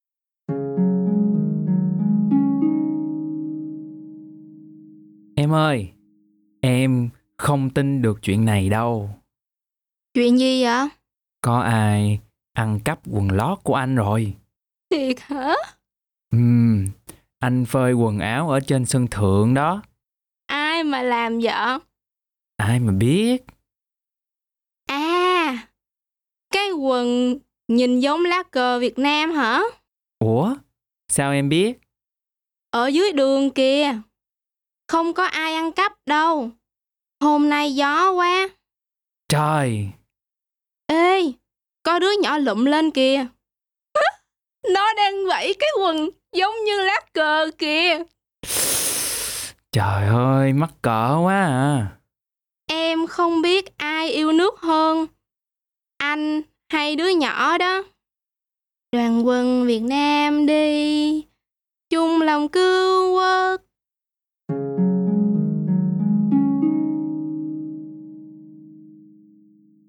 E082_dialogue.mp3